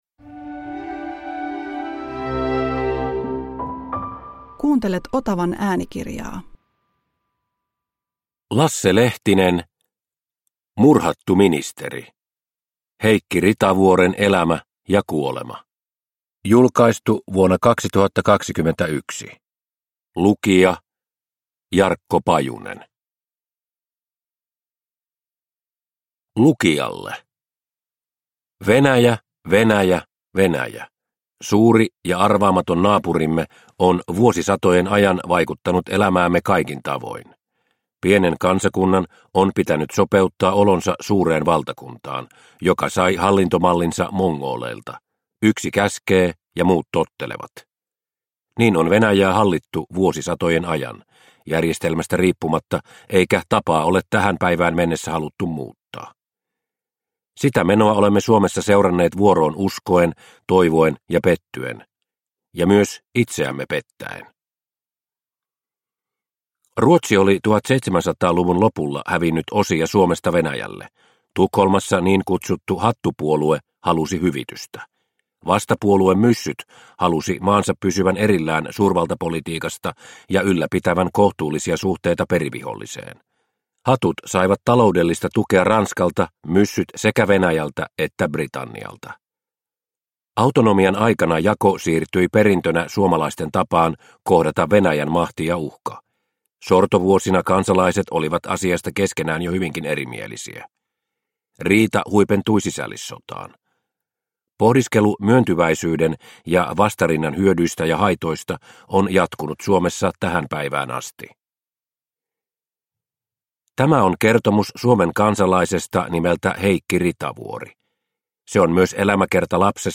Murhattu ministeri – Ljudbok – Laddas ner